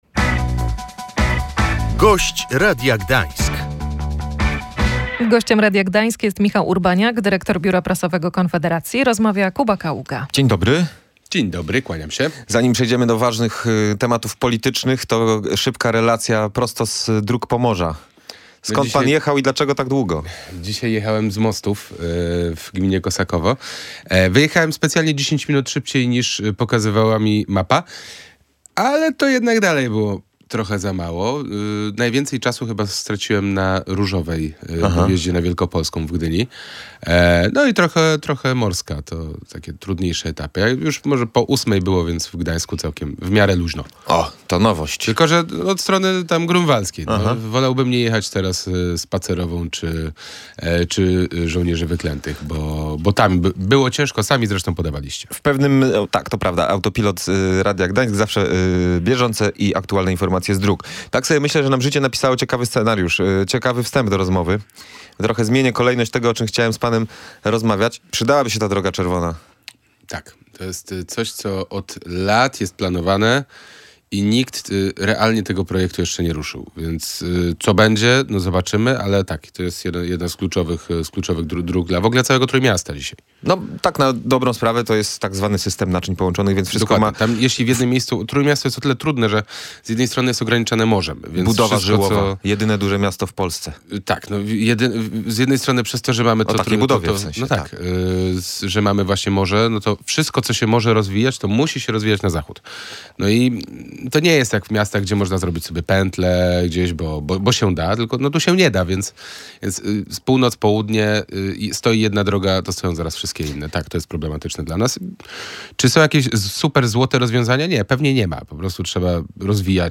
Michał Urbaniak mówił w Radiu Gdańsk, że Konfederacja ma inną propozycję.